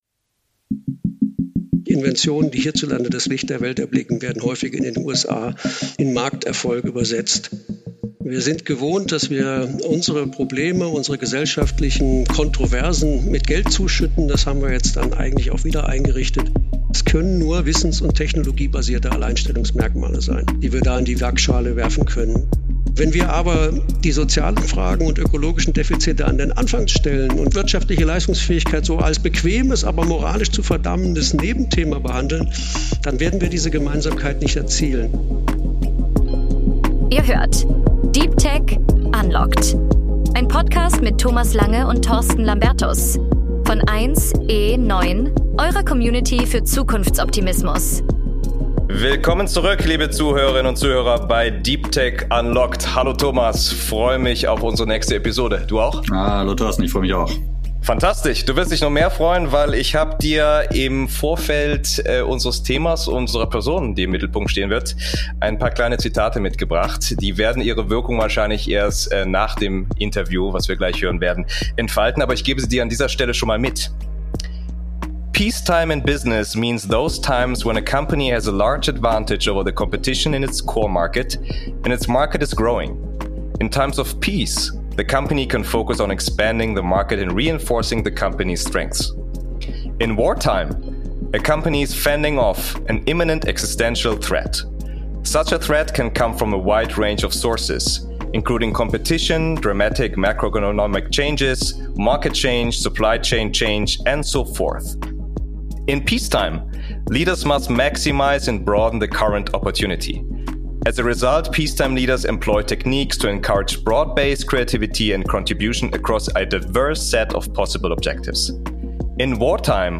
In der zweiten Folge von DeepTech Unlocked ist mit Christoph M. Schmidt einer der renommiertesten Ökonomen des Landes zu Gast.